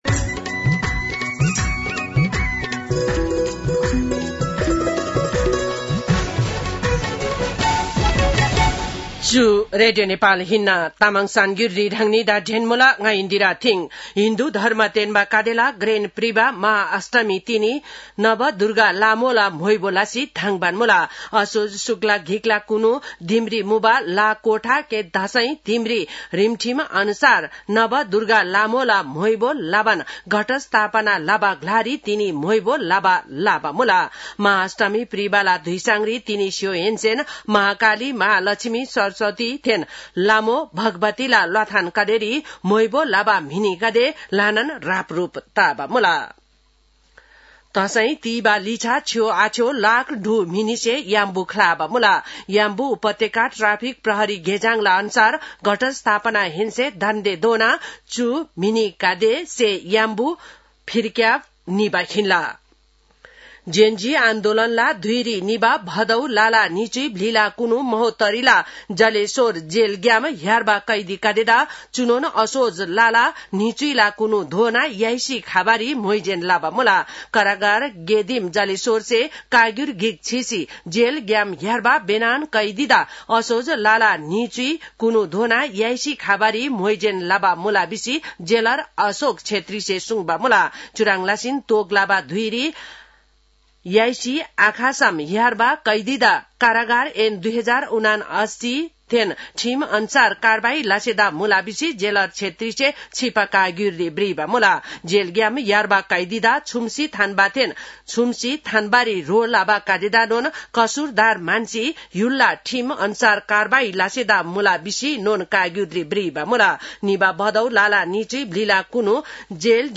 तामाङ भाषाको समाचार : १४ असोज , २०८२